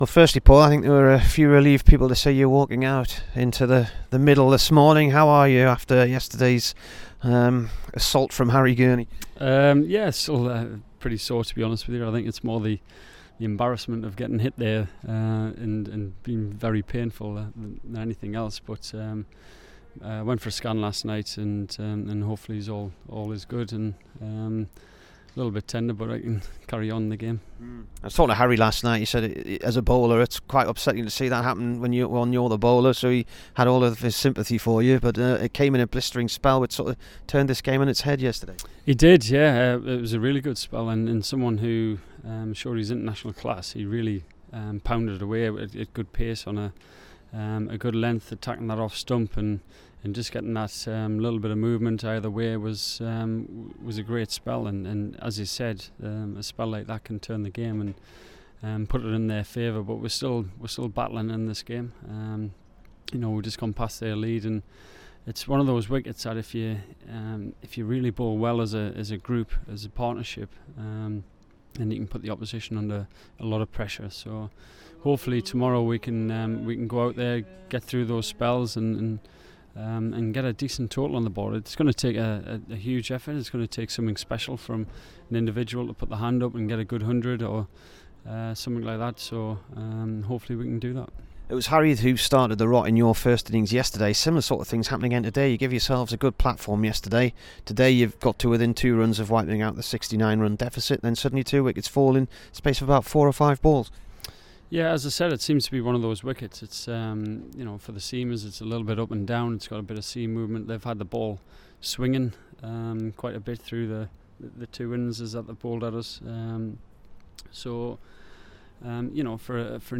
HERE'S THE DURHAM CAPTAIN AFTER DAY TWO V NOTTS.